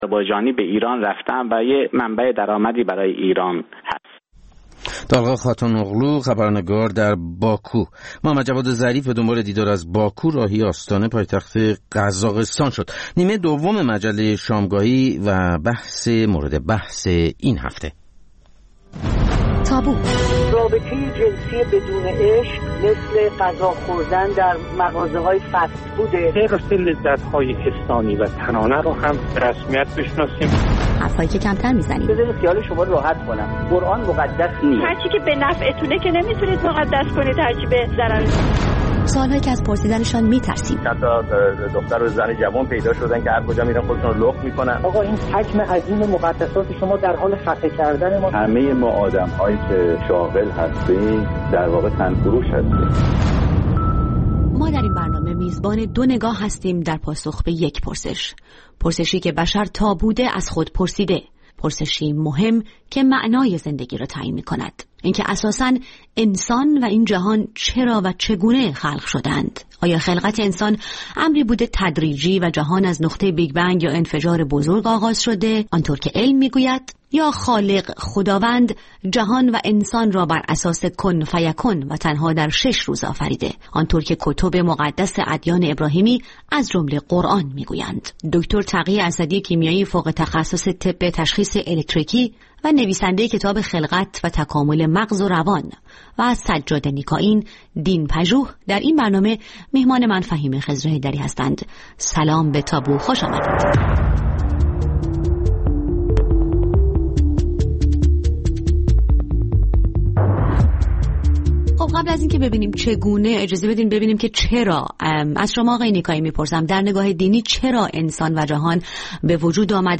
با دو‌ مهمانِ برنامه